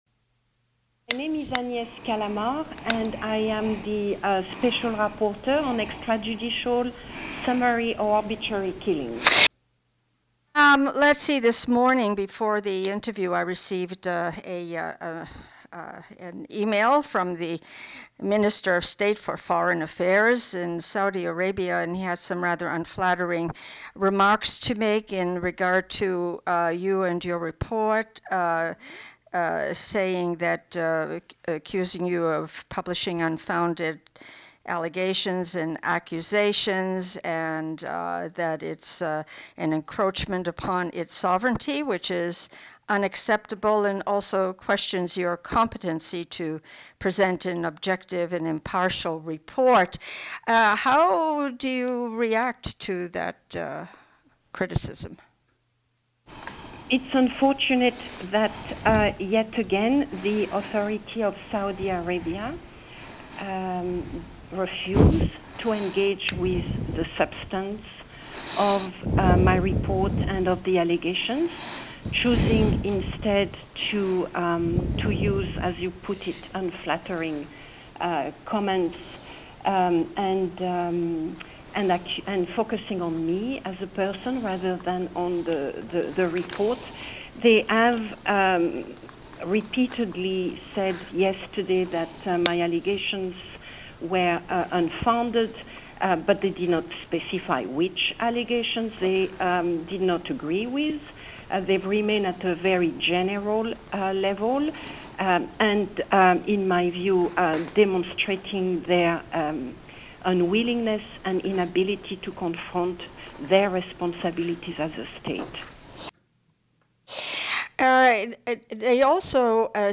Interview: UN Special Rapporteur Callamard on Khashoggi Report